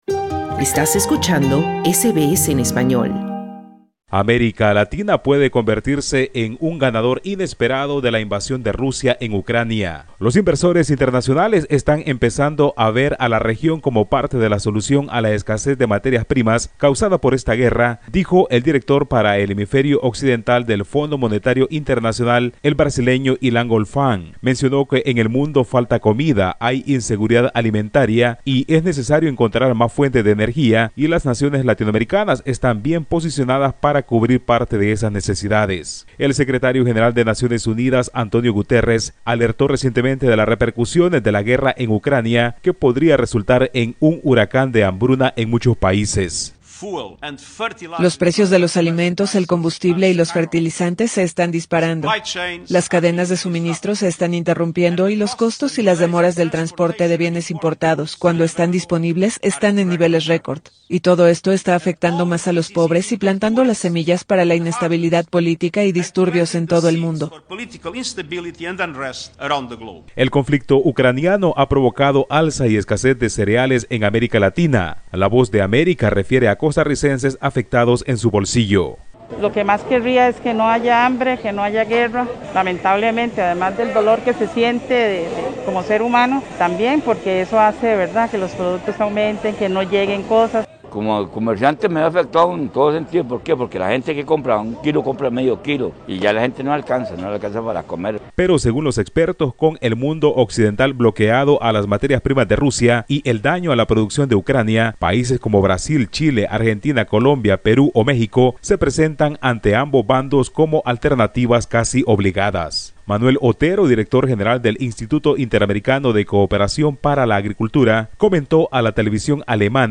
Ante la escasez de materias primas causada por la guerra, los inversores internacionales ven a Latinoamérica como solución y el Fondo Monetario Internacional señala que en esa región hay países con una destacada producción agrícola y otros con capacidad petrolífera y de metales. Escucha el informe del corresponsal de SBS Spanish en Latinoamérica